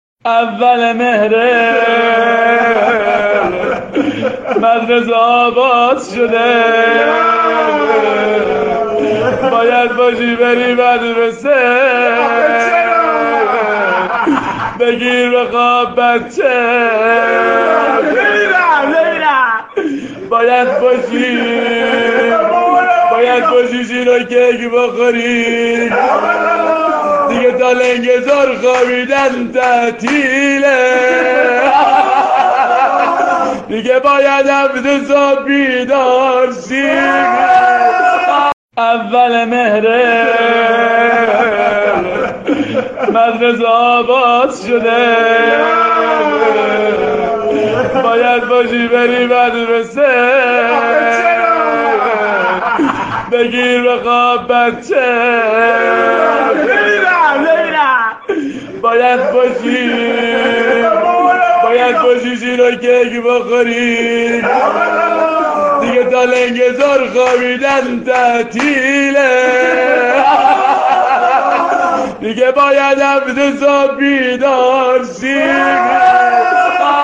مداحی معروف اینستاگرام